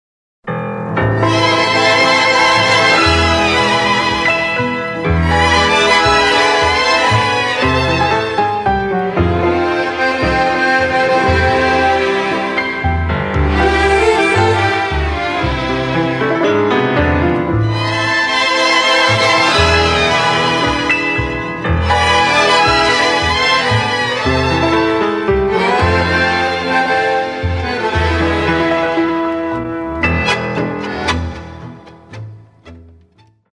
Klassische Tangos (wo ist die Grenze?)